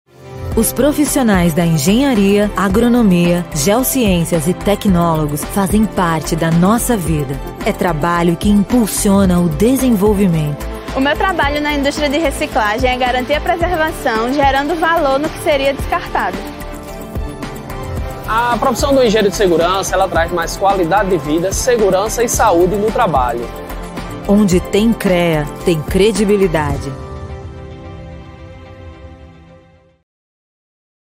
Versatile and charismatic voice. Natural style, which can be interpreted in a lively and energetic way to capture the audience's attention, or in a smooth, pleasant and interesting way to keep listeners connected and attentive.
Pro Home Studio- Acoustically treated WhisperRoom, Neumann TLM102 mic, Scarlett Solo Focusrite Interface and Pro tools daw
Main Demo
Versatile, Natural, Female